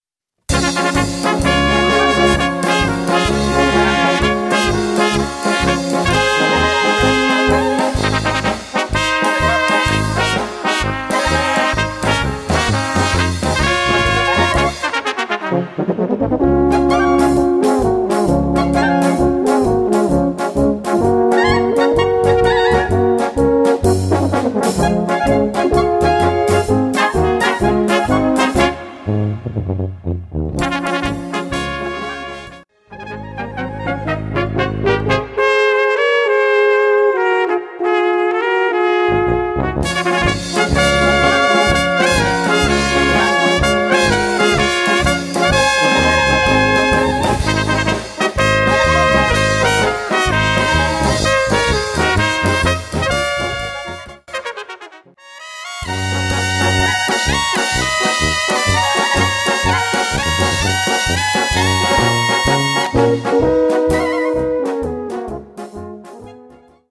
gutklingende, schwungvolle Polka